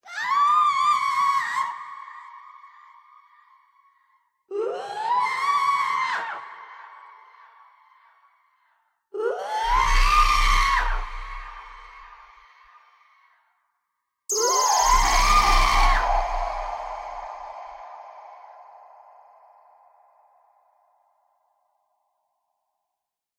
Здесь вы найдете саундтреки, фоновые шумы, скрипы, шаги и другие жуткие аудиоэффекты, создающие неповторимую атмосферу ужаса.
Lady Screams Again